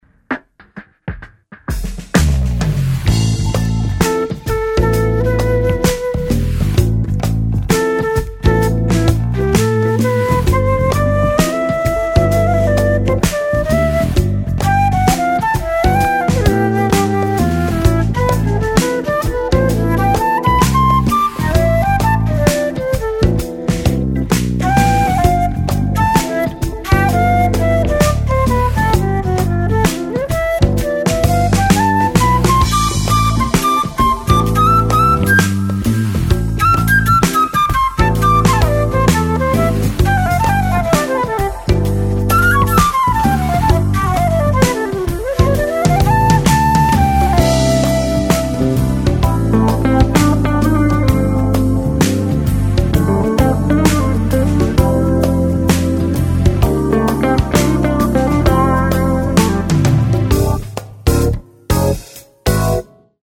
Piano, Keyboards & Programming
Basses
Guitars & Electric Sitar
Drums & Timbales
Percussion
Tenor, Soprano, Altosax & Flutes